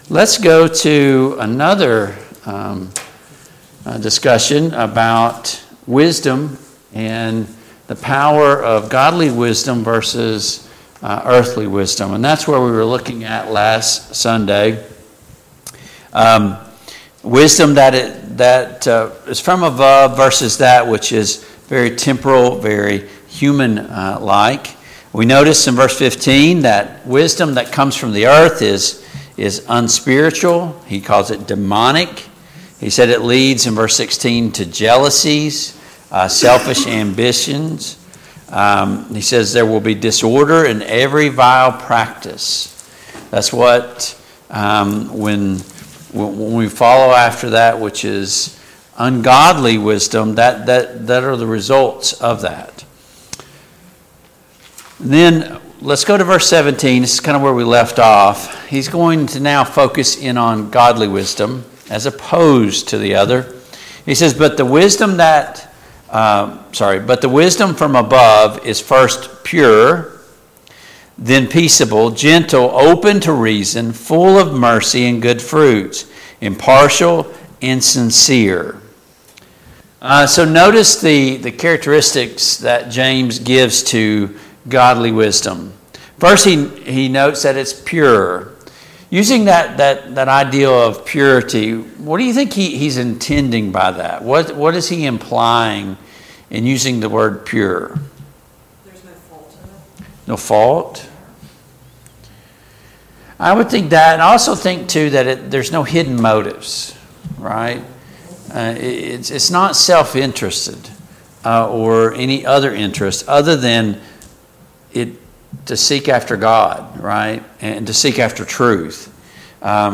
Passage: James 3:17-18, James 4:1-5 Service Type: Family Bible Hour